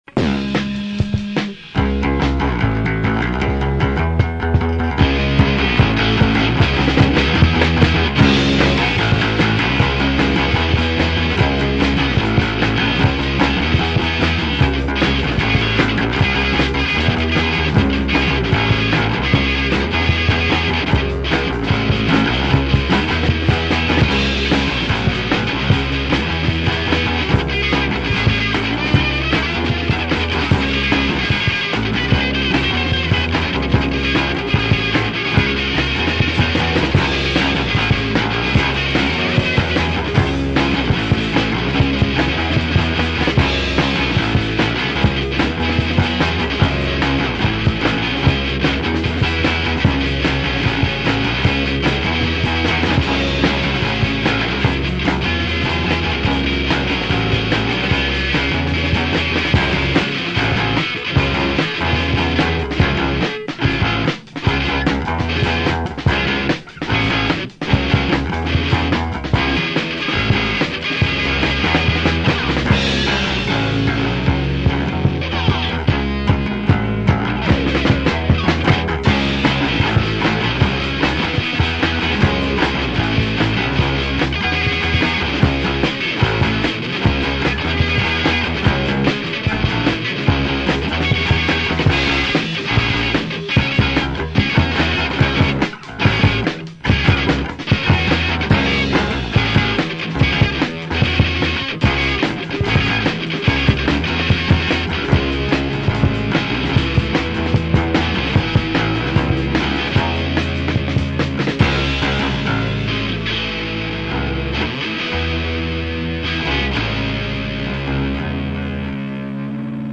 Classic 1990s Lehigh Valley punk
punk rock See all items with this value
cassette